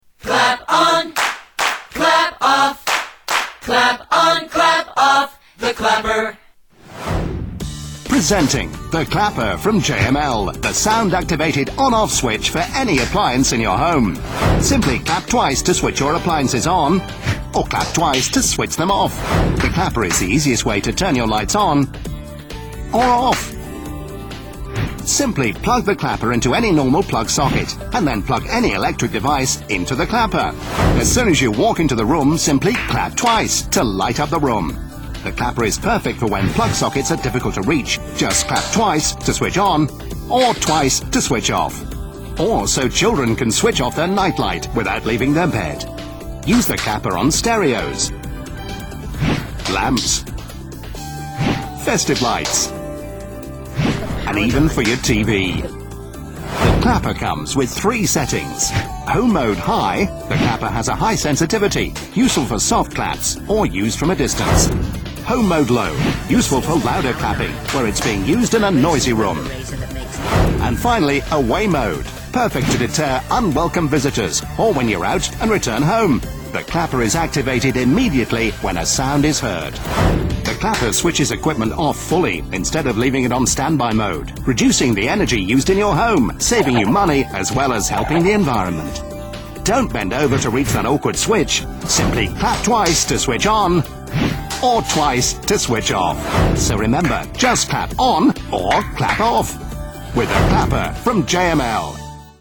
Brittish version
Tags: The Clapper The Clapper clips The Clapper sounds The Clapper ad The Clapper commercial